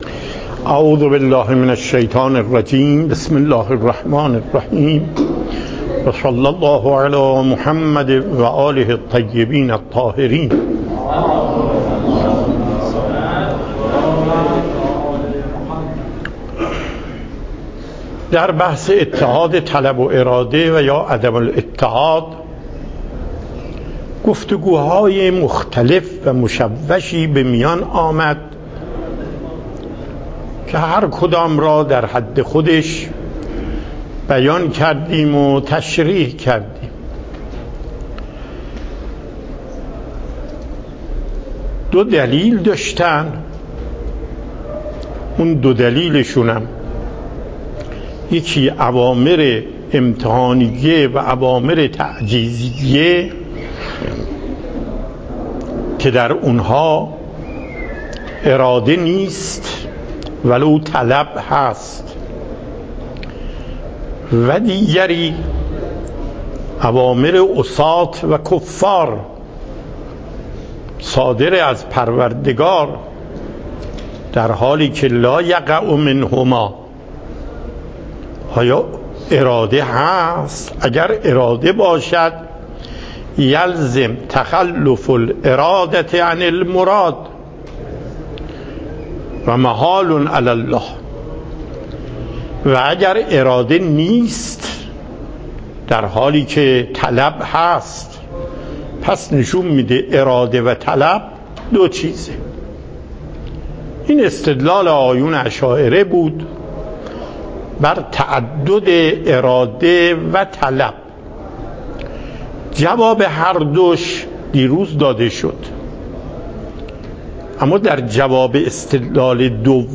درس اصول آیت الله محقق داماد